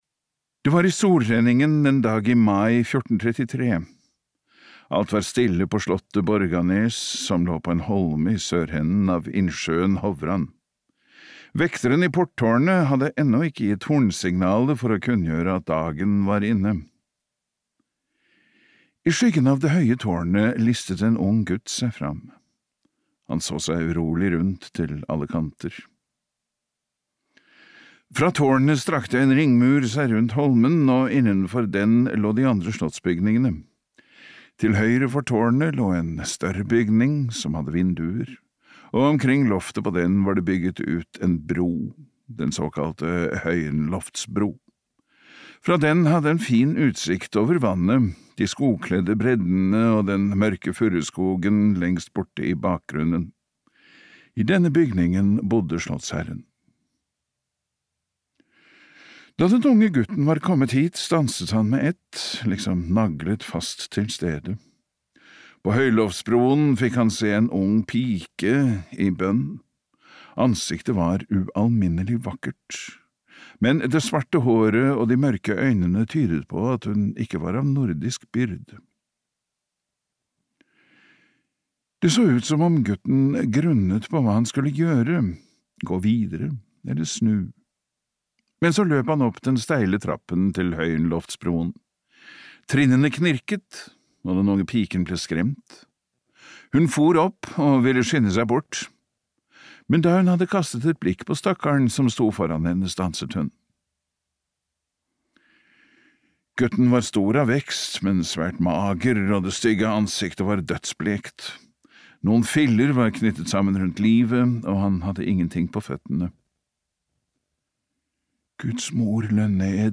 Engelbrekt engelbrektsson (lydbok) av Carl Georg Starbäck